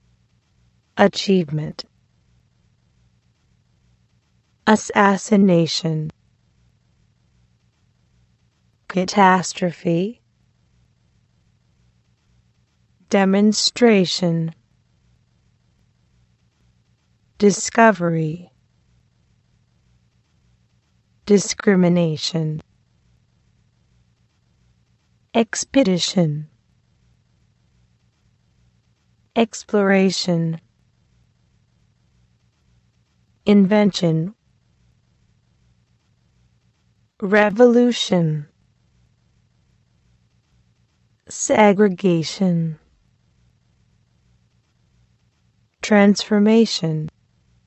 This glossary focuses on different nouns describing major events around the world. Please listen and repeat twice.
Este vocabulario presenta una serie de sustantivos que describen diferentes acontecimientos importantes alrededor del mundo. Escucha y repíte dos veces.